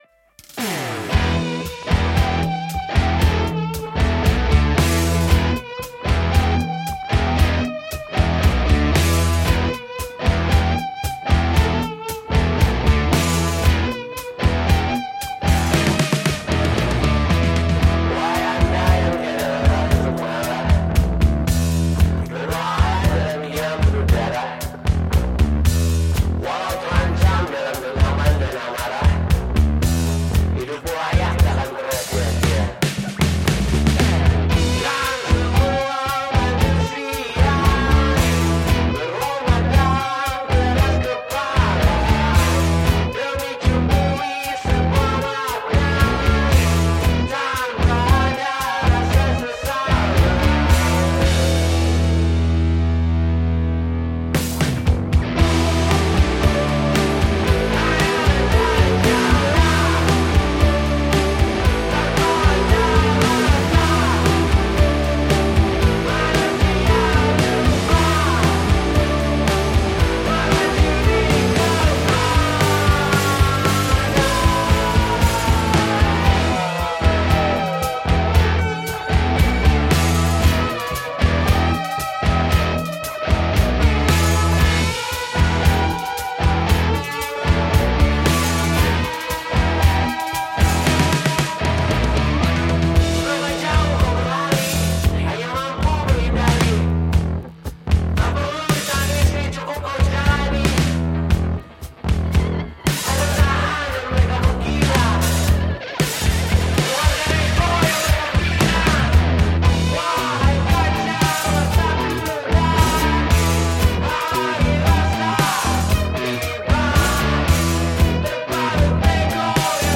Bandung Blues
sedikit sentuhan Rock dan Psychadelic